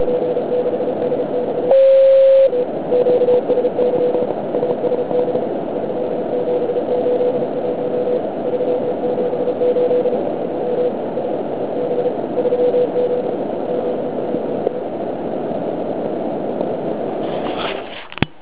V�dy� i takovou VP6DX lze poslouchat i na NE612 obvod.
Kmito�et 3502 KHz, TCVR SW80+.